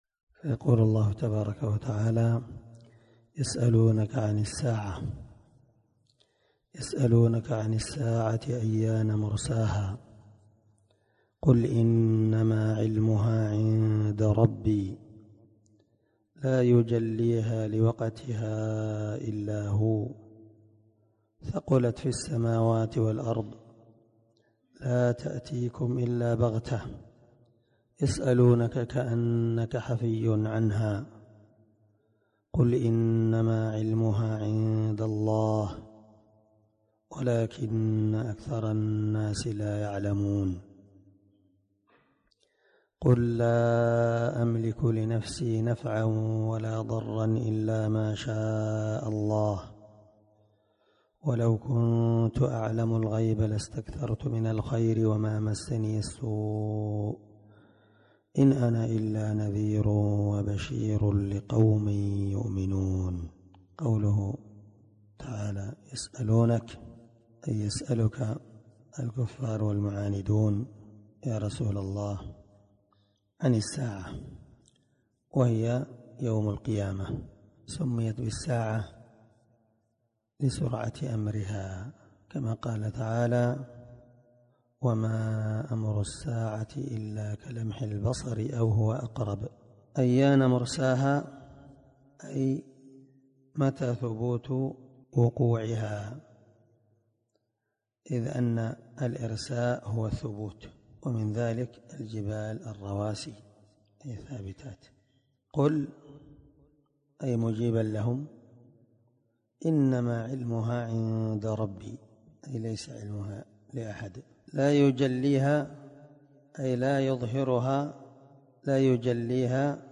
497الدرس 49تفسير آية ( 187 - 188 ) من سورة الأعراف من تفسير القران الكريم مع قراءة لتفسير السعدي
دار الحديث- المَحاوِلة- الصبي